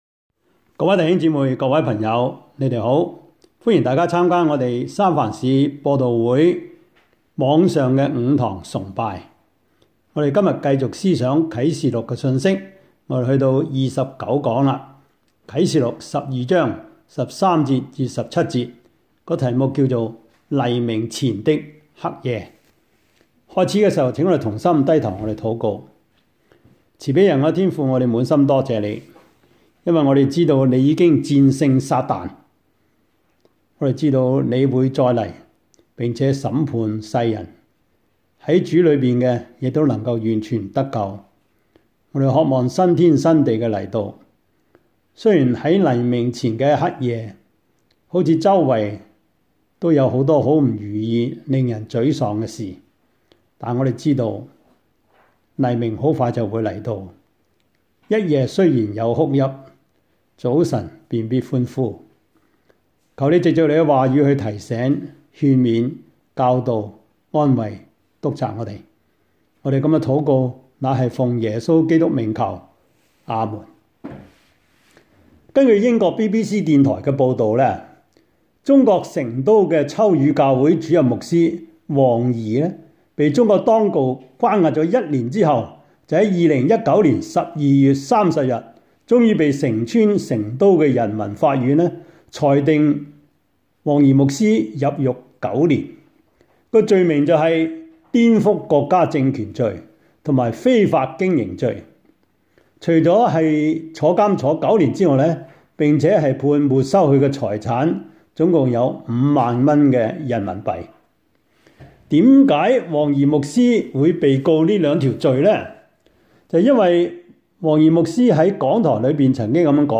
Service Type: 主日崇拜
Topics: 主日證道 « 奉主的差遣出去傳道 平安夜 »